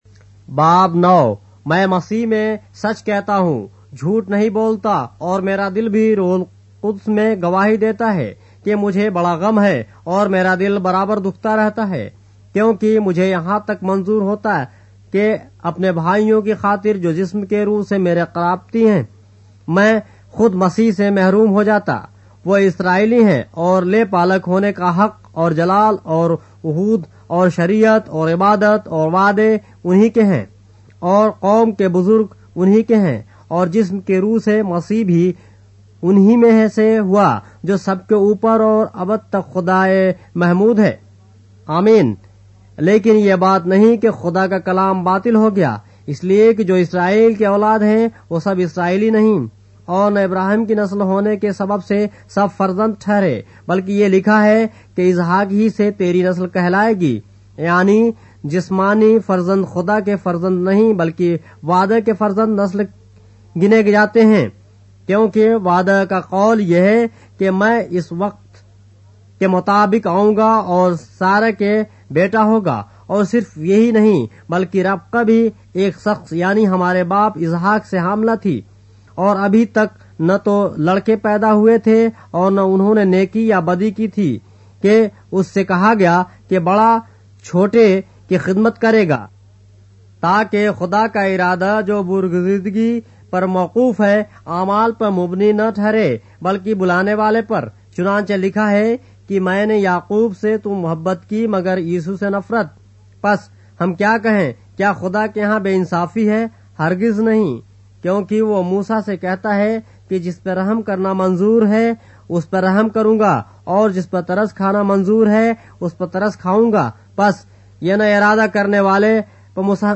اردو بائبل کے باب - آڈیو روایت کے ساتھ - Romans, chapter 9 of the Holy Bible in Urdu